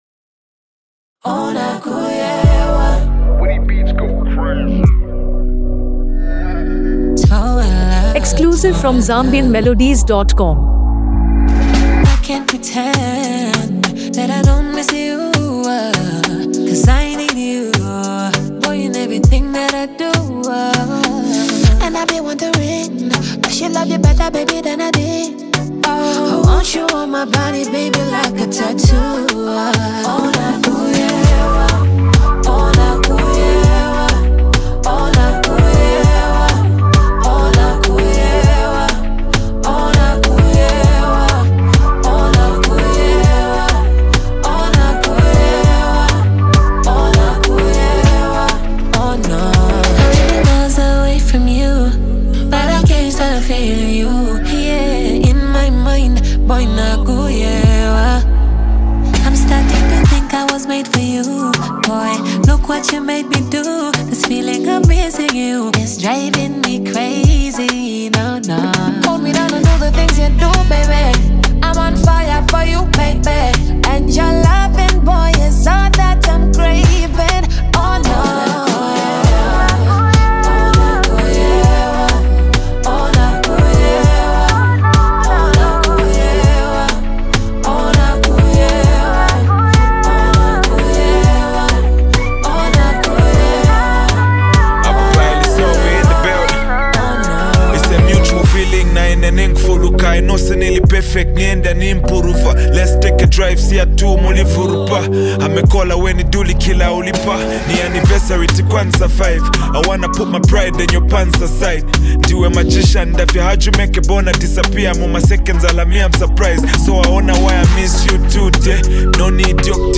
wrapped in a melodious Afro-pop and R&B fusion.